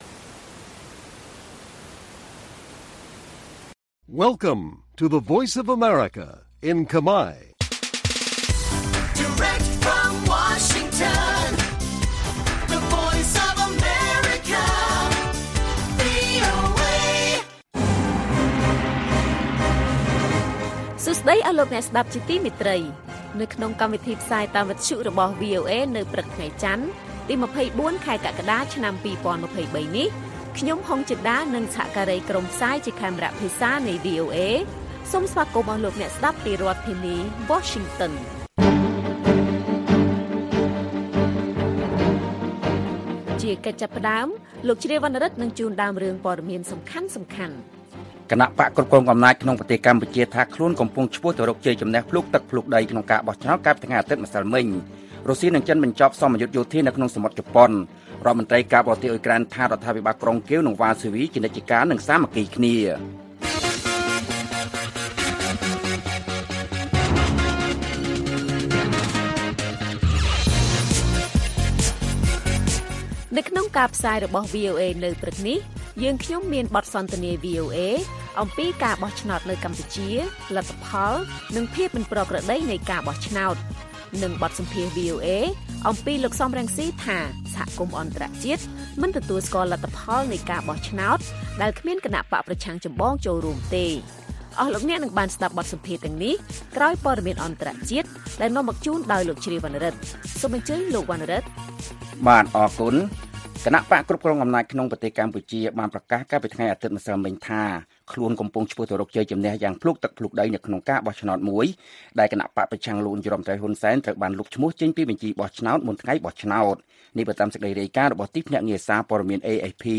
ព័ត៌មានថ្ងៃនេះមានដូចជា បទសន្ទនា VOA អំពីការបោះឆ្នោតនៅកម្ពុជា លទ្ធផលនិងភាពមិនប្រក្រតីនៃការបោះឆ្នោត។ បទសម្ភាសន៍ VOA អំពីលោក សម រង្ស៉ី ថា សហគមន៍អន្តរជាតិមិនទទួលស្គាល់លទ្ធផលនៃការបោះឆ្នោតដែលគ្មានគណបក្សប្រឆាំងចម្បងចូលរួម និងព័ត៌មានផ្សេងៗទៀត៕